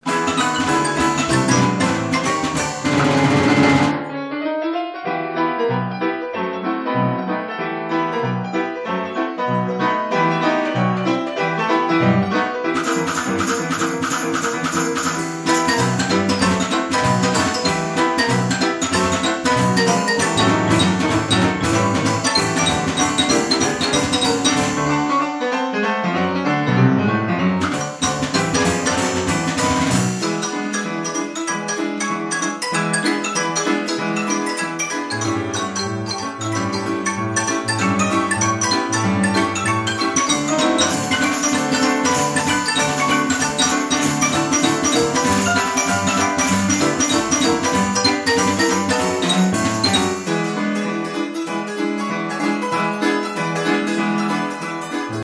NELSON-WIGGEN STYLE 6X Vol.1, vol. 2